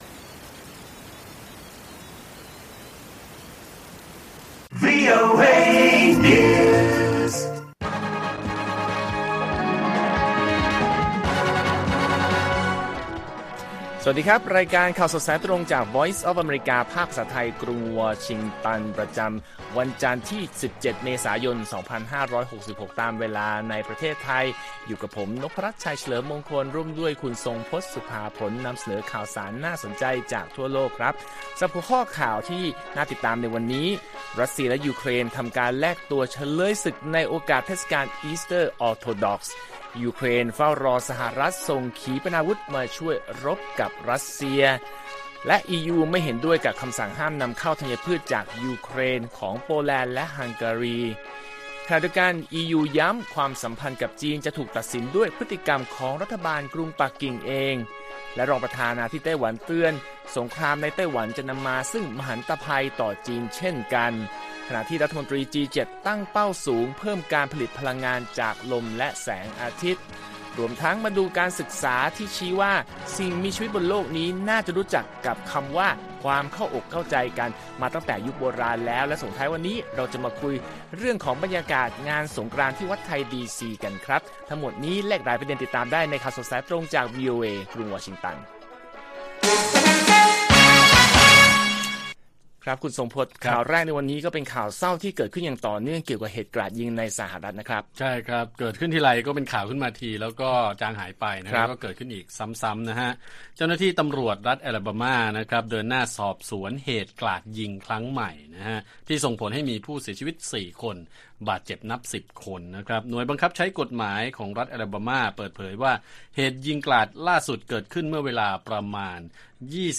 ข่าวสดสายตรงจากวีโอเอไทย 6:30 – 7:00 น. 17 เม.ย. 2566